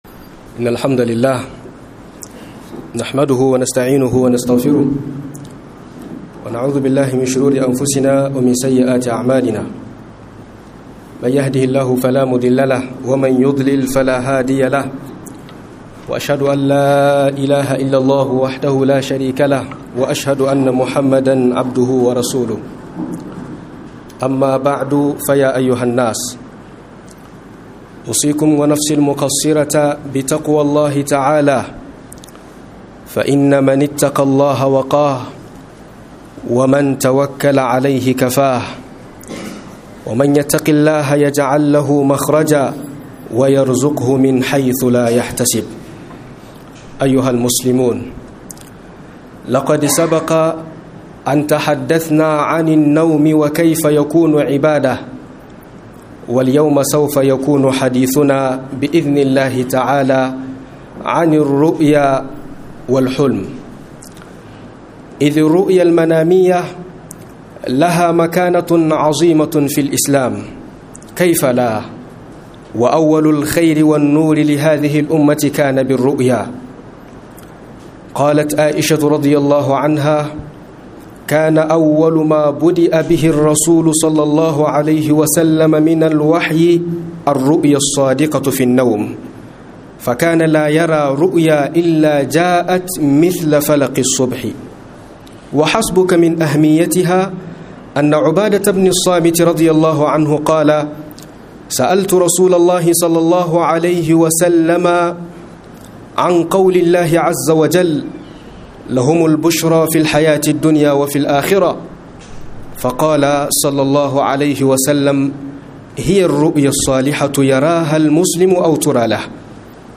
MAFARKI DA HUKUNCIN SA A MUSULUNCI - MUHADARA